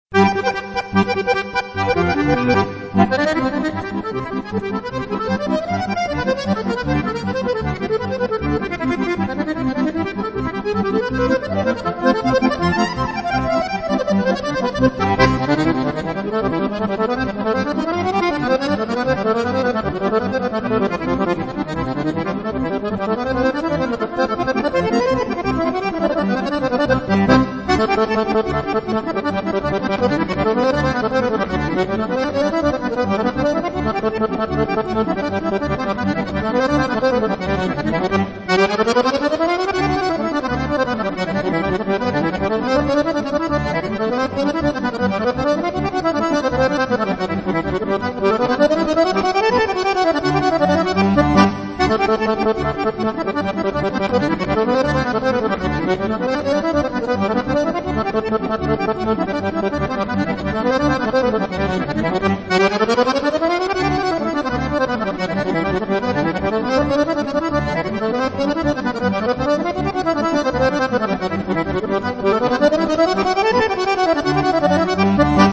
Rock, jazz, polcas, bailes de Auvernia (bourrées), valses, java, tango, madison, fox-trot, gaita, folk, musicas Tex-Mex, cajun y zydéco, musicas traditionnales, swing, gitanos y swing-musette, etc; el accordeón se presta de buen grado a todas las clases musicales.
polca hace clic   Para escuchar un